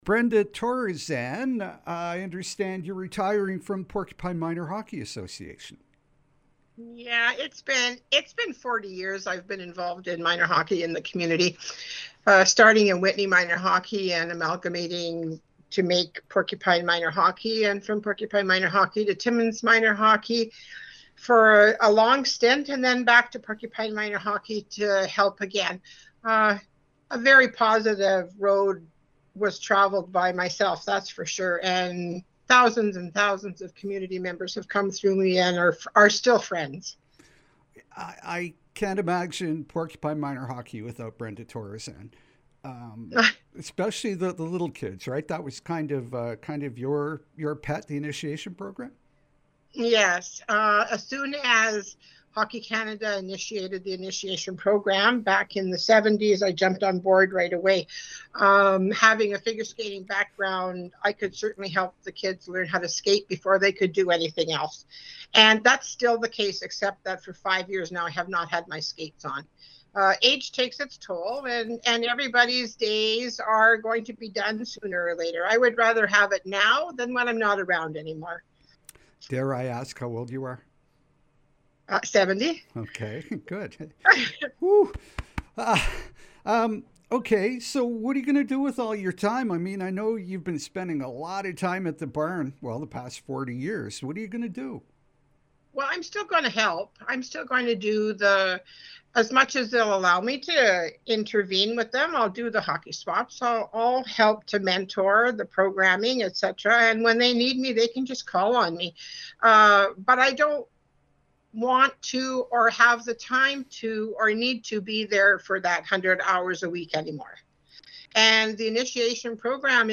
Here’s our interview: